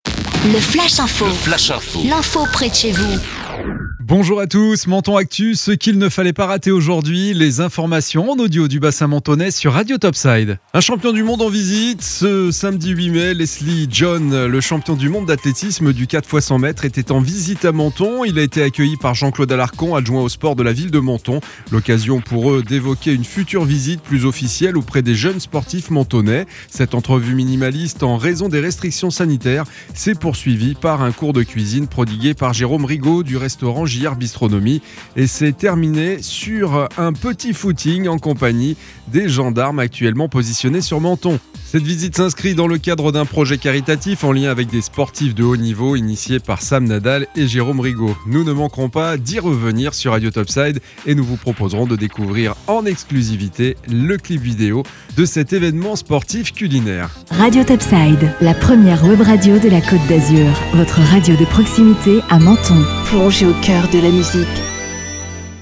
Menton Actu - Le flash info du lundi 10 mai 2021